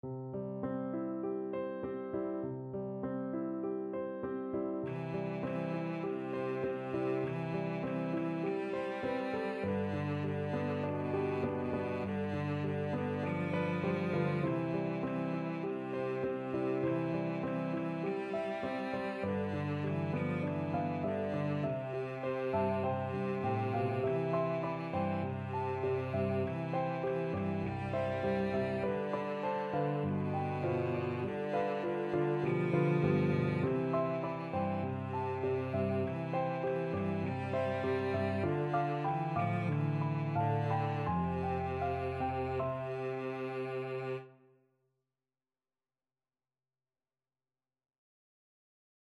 Cello
Gently Flowing = c.100
C major (Sounding Pitch) (View more C major Music for Cello )
4/4 (View more 4/4 Music)
B3-G4